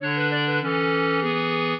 clarinet
minuet6-5.wav